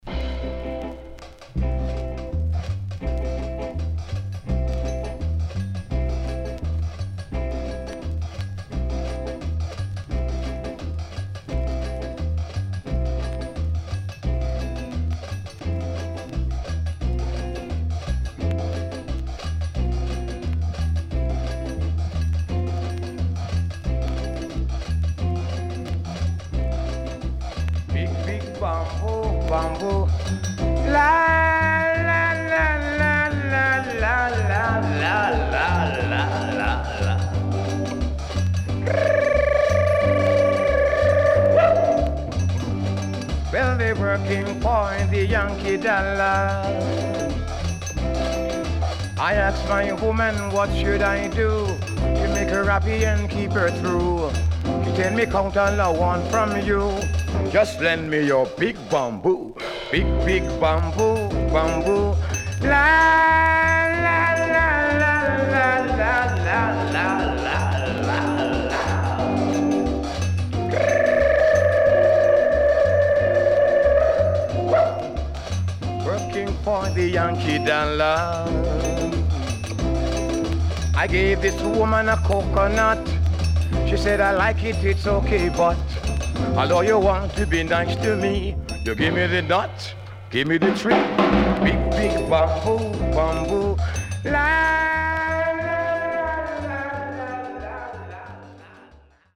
Rare.Jamaian Calypso